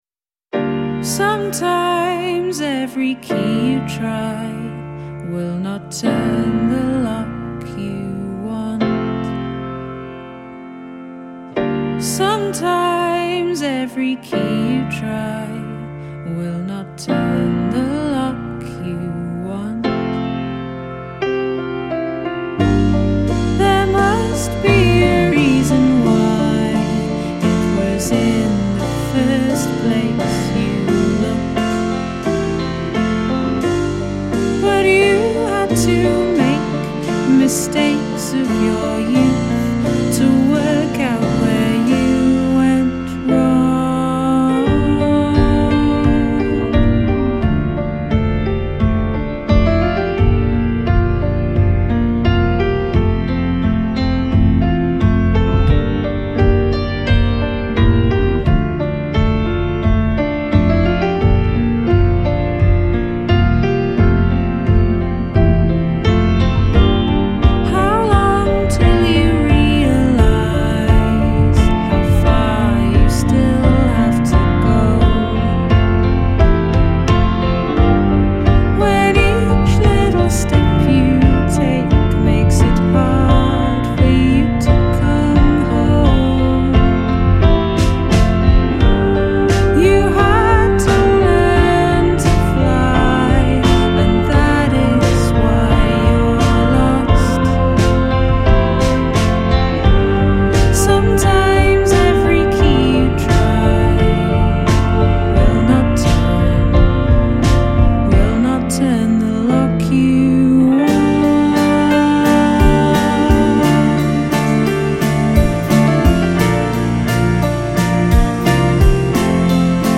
Singer-songwriter and multi-instrumentalist
tasteful, confessional alt-pop
Combining bold piano lines with striking vocal delivery